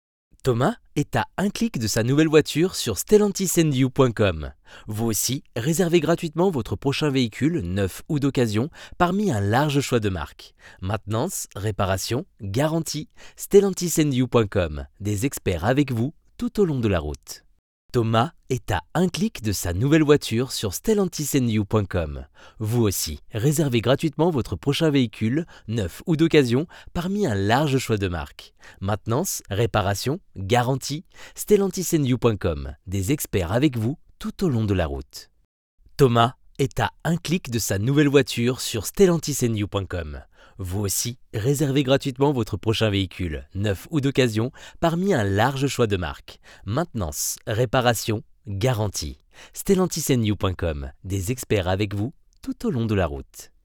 Voix off
- Ténor